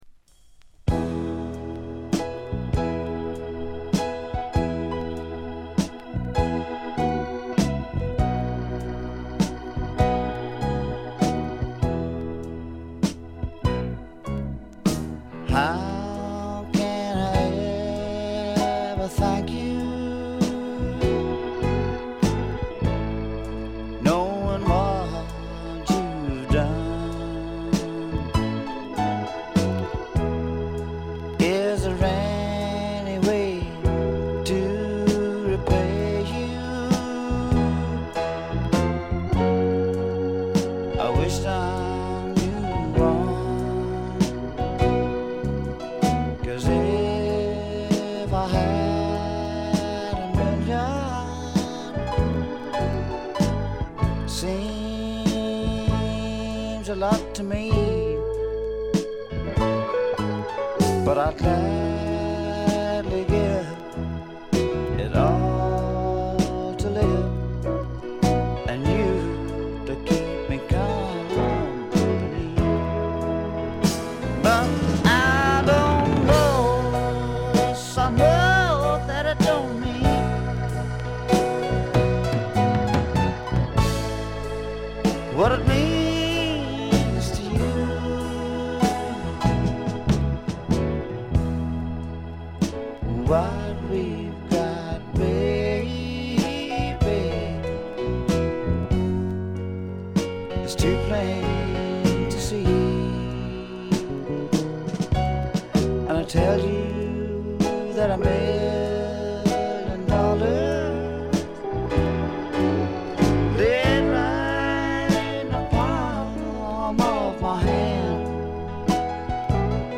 微細なノイズ感のみ。
ルーズでちょこっと調子っぱずれなあの愛すべきヴォーカルがまたよくて、本作の雰囲気を盛り上げています。
試聴曲は現品からの取り込み音源です。